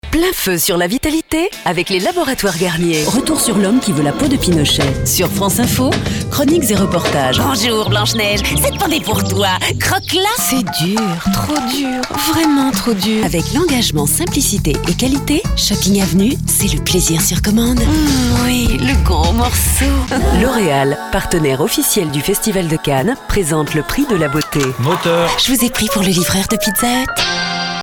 VOIX FEMININE FRANCAISE MEDIUM.PUBLICITES/FILMS INSTITUTIONNELS? E-LEARNONG, VOICE OVER, ETC...
Sprechprobe: Werbung (Muttersprache):
FRENCH FEMALE VOICE TALENT.